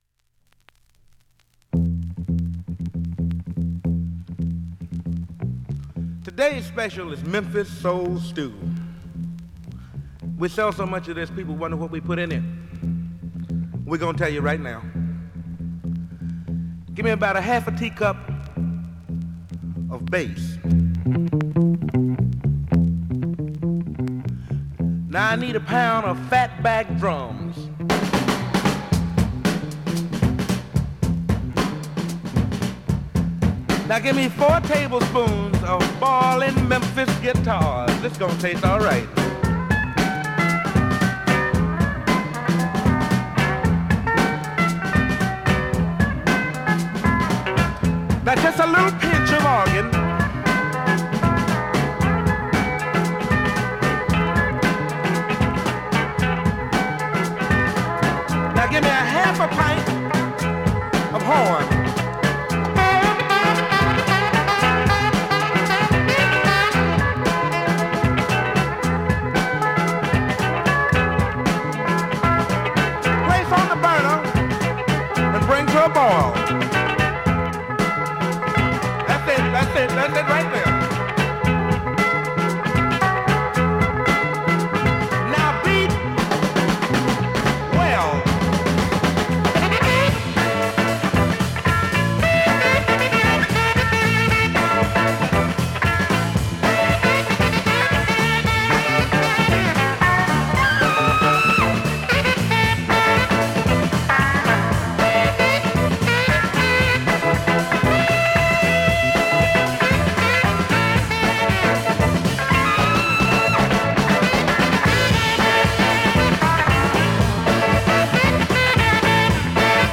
SOUL、FUNK、JAZZのオリジナルアナログ盤専門店
現物の試聴（両面すべて録音時間６分）できます。